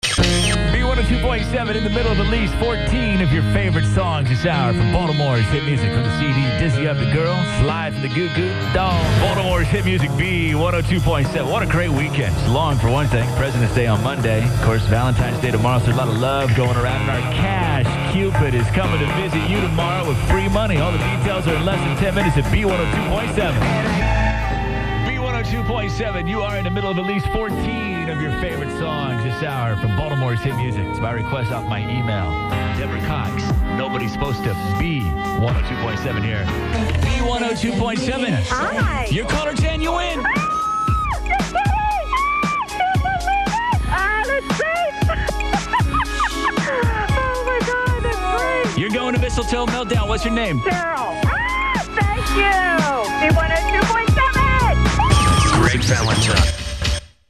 AFTERNOONS AIRCHECK